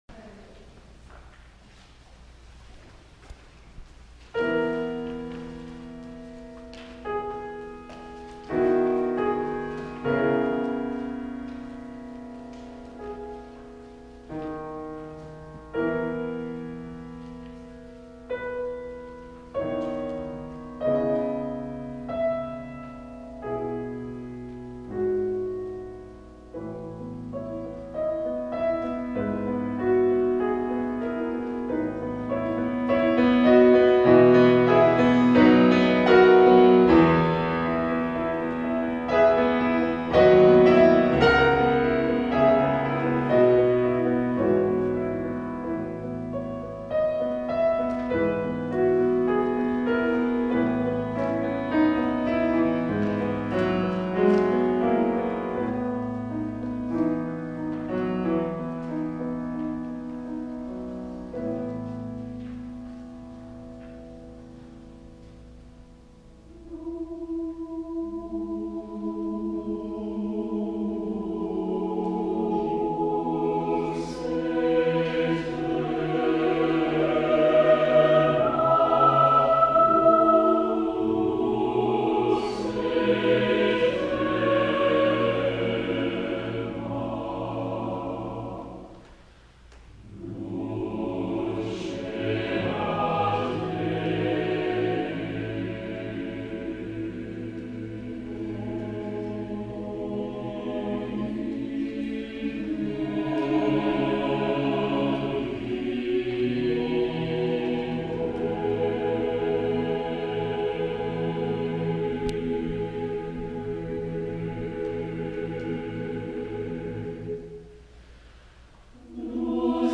Accompaniment:      Piano, Soprano Solo
Music Category:      Choral